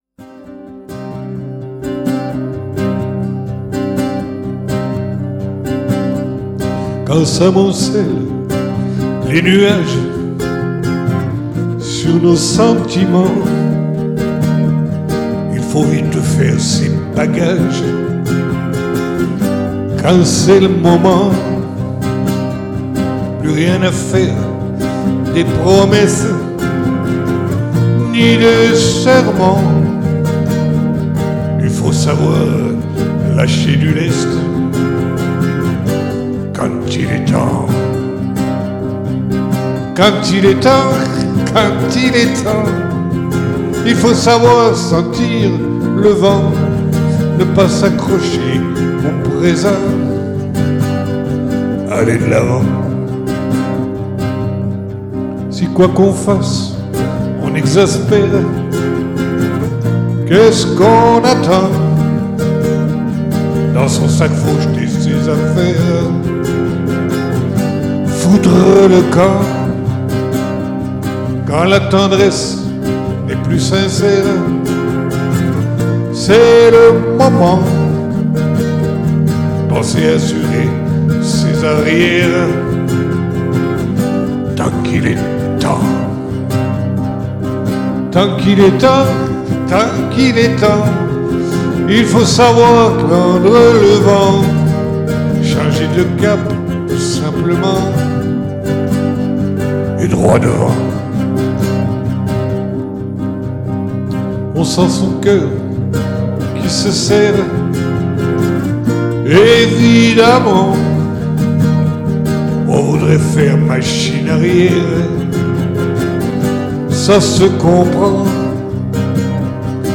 Capo 3°
chant